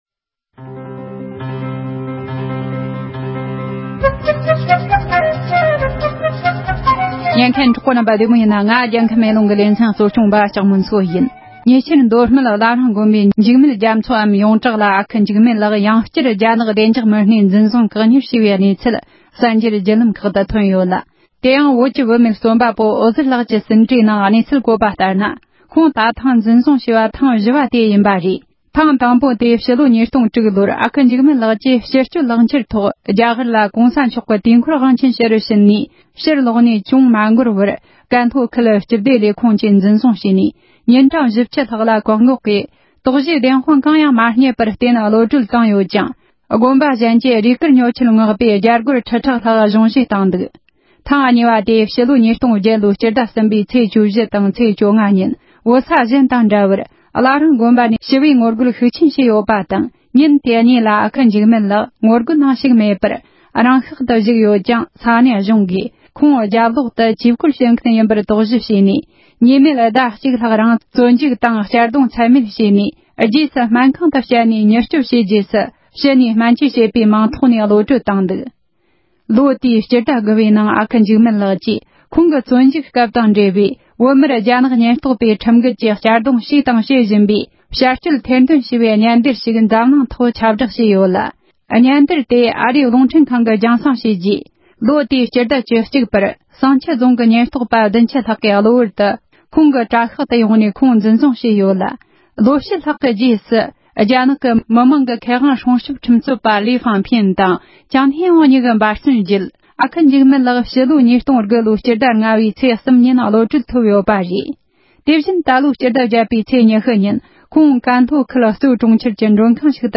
འབྲེལ་ཡོད་མི་སྣར་བཅར་འདྲི་ཞུས་པ་ཞིག་ལ་གསན་རོགས་གནོངས༎